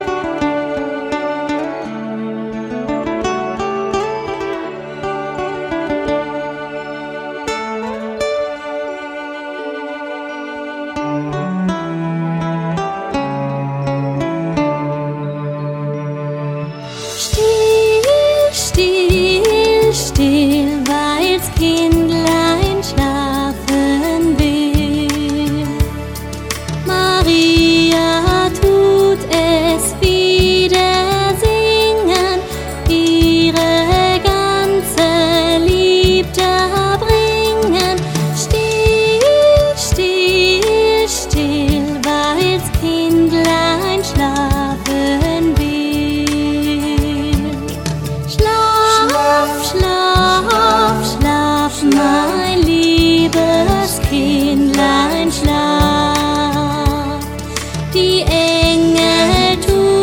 Instrumental, Kinderlieder